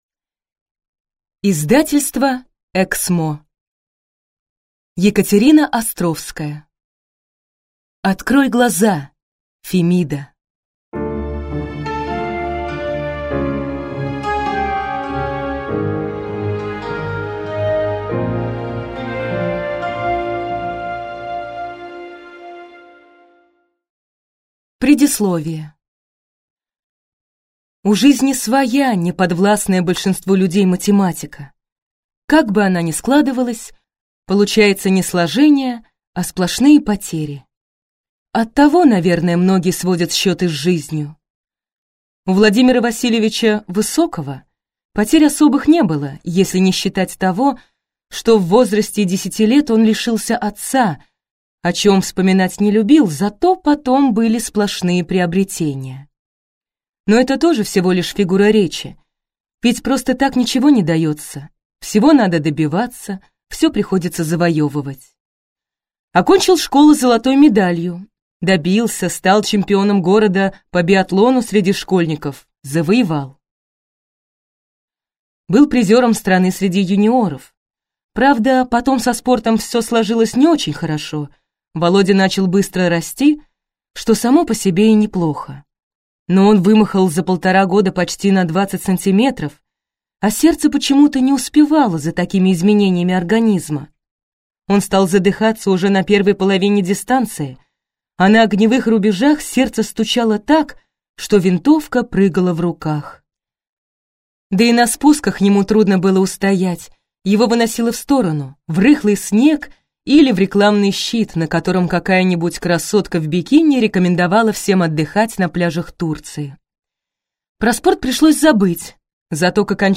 Аудиокнига Открой глаза, Фемида!
Прослушать и бесплатно скачать фрагмент аудиокниги